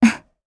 Xerah-Vox_Happy1_jp.wav